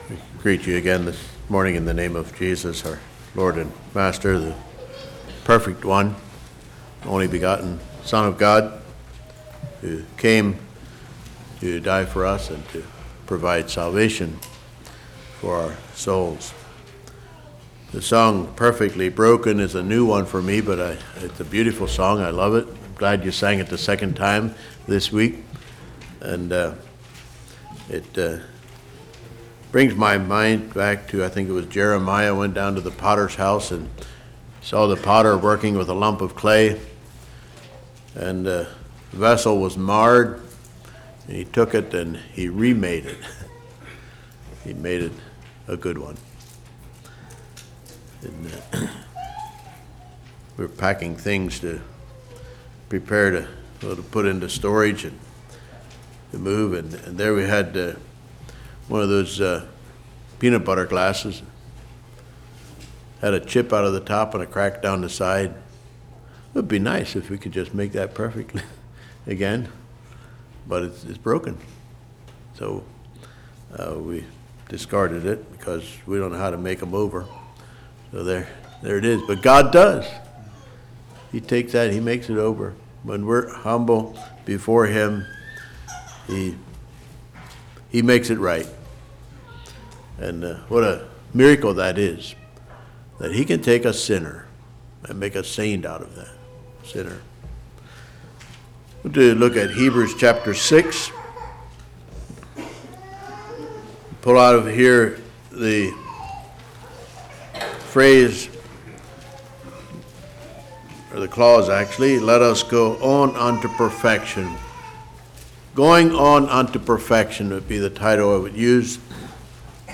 Congregation: Leola
Sermon